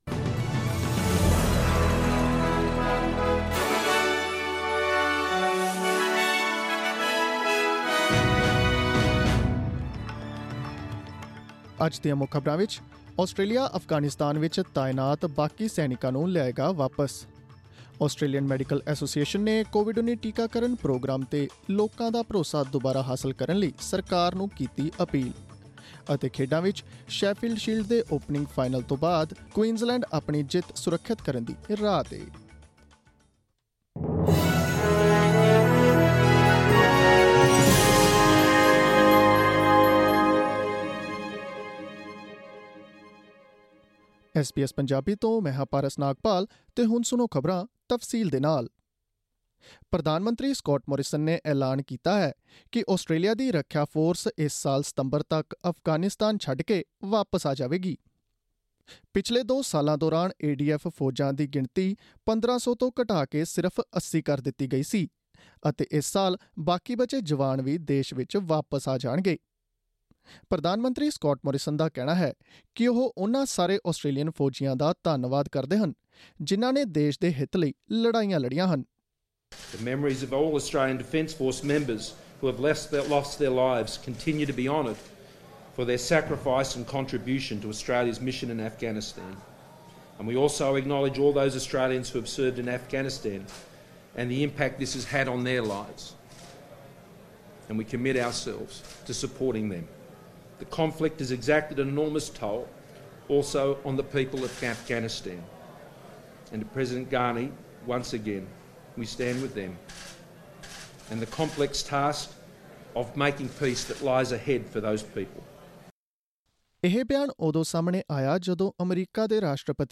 Click on the audio icon in the picture above to listen to the news bulletin in Punjabi.